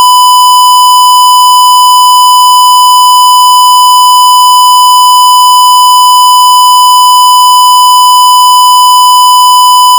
square_sinc.wav